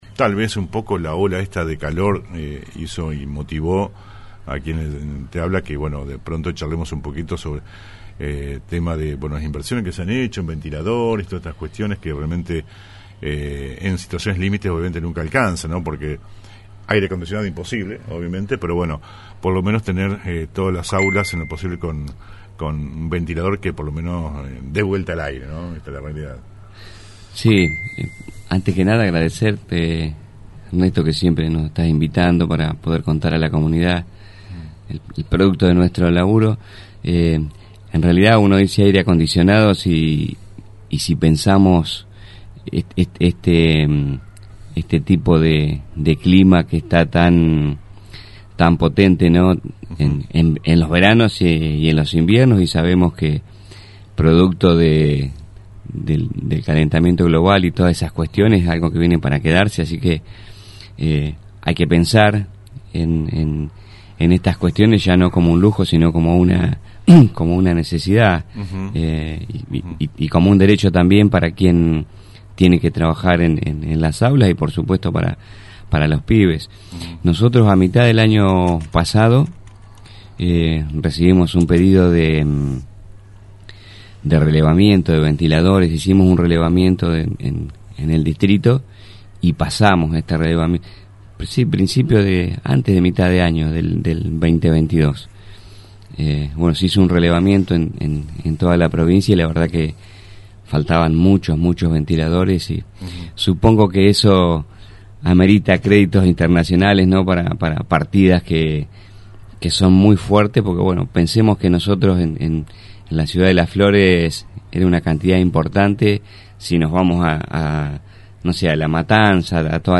Comenzaba la entrevista en “El Periodístico” El Pte.